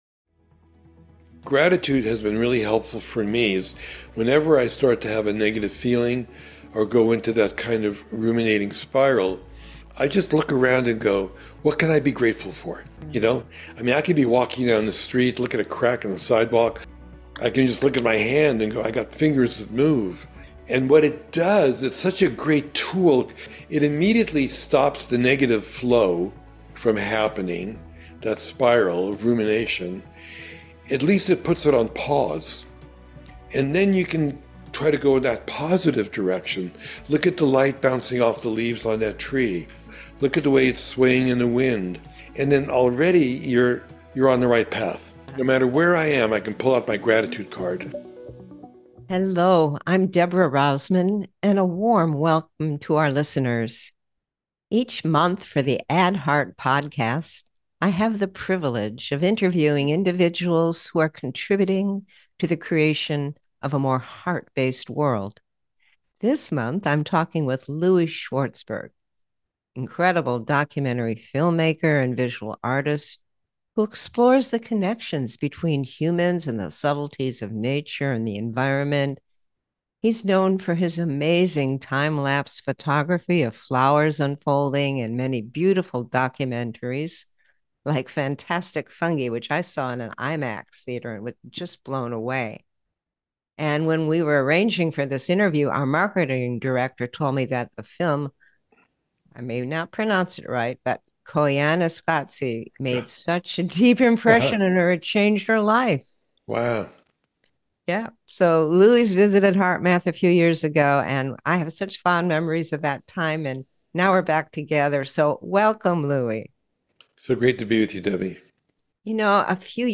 Guest: Louie Schwartzberg Ever wonder what happens to your physiology when gratitude goes deeper than just “thank you”?